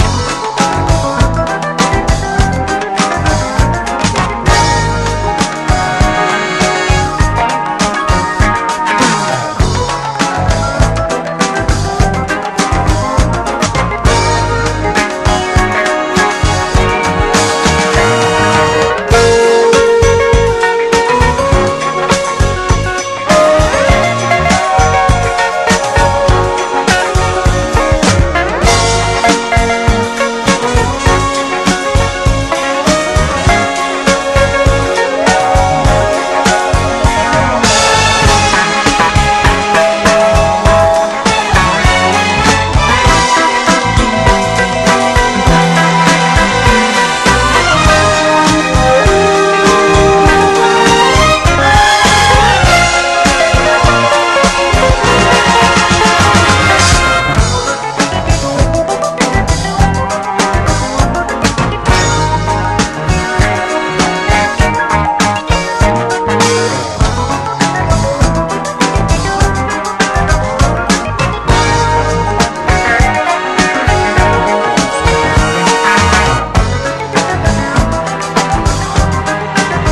JAZZ / OTHER / JAZZ FUNK / DRUM BREAK / SAMPLING SOURCE
ドラム・ブレイク満載！